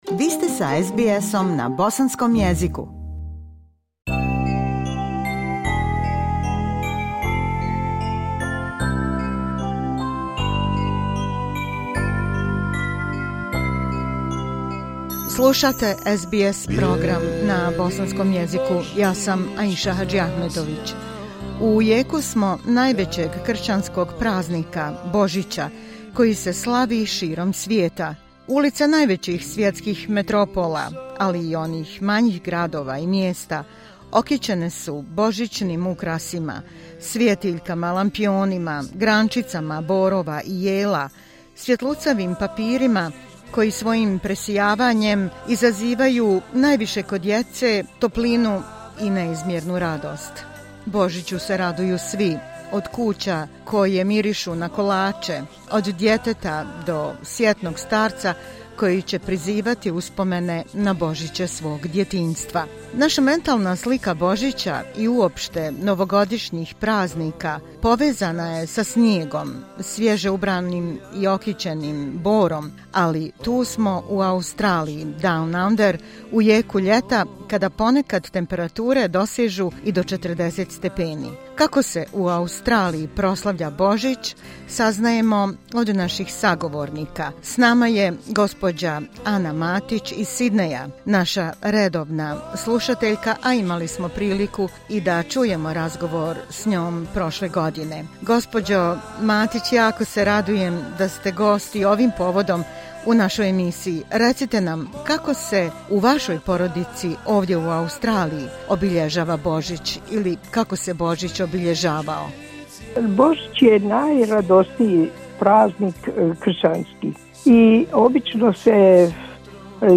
Kršćani cijelog svijeta proslavljaju njihov najveći i najradosniji blagdan - Božić, pa smo tim povodom napravili malu reportažu o Božiću u Australiji, kako ga proslavljaju članovi bosanske i australijske zajednice ovdje downunder, u vrijeme dok cvjetaju magnolije i eukaliptusi...